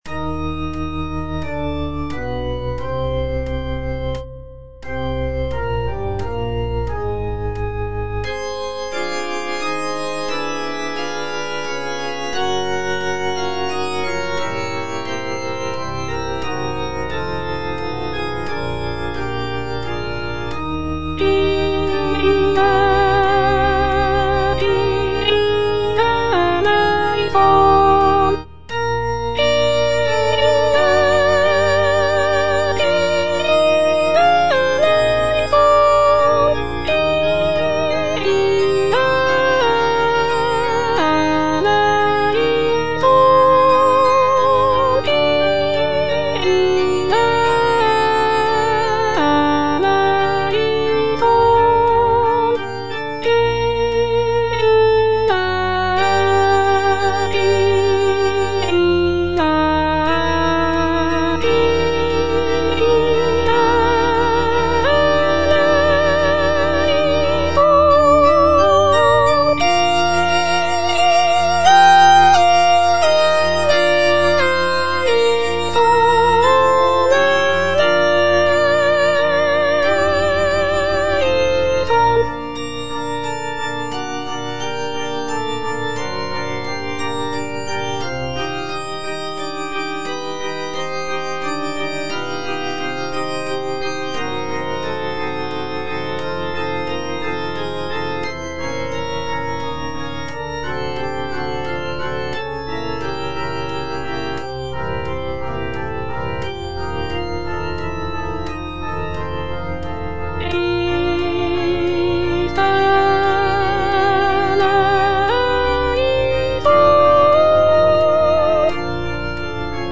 G. FAURÉ, A. MESSAGER - MESSE DES PÊCHEURS DE VILLERVILLE Kyrie - Soprano (Voice with metronome) Ads stop: auto-stop
The composition is a short and simple mass setting, featuring delicate melodies and lush harmonies.